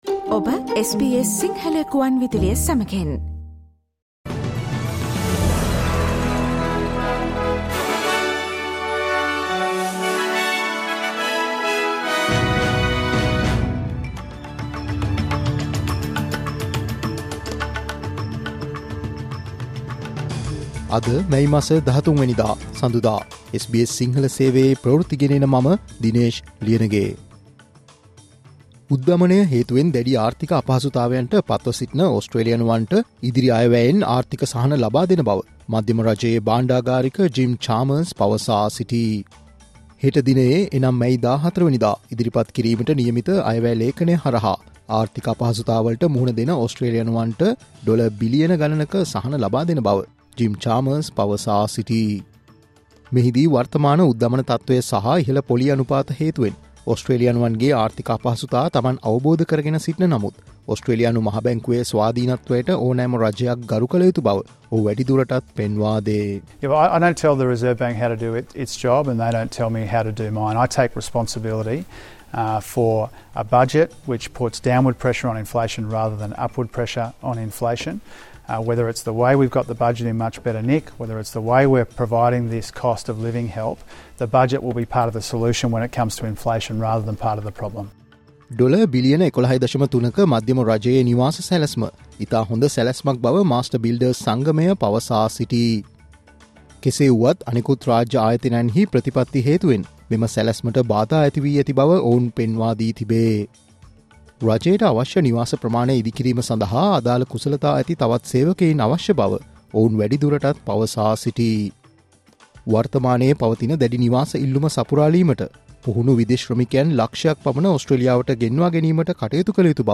Australian news in Sinhala, foreign and sports news in brief - listen, Sinhala Radio News Flash on Thursday May 13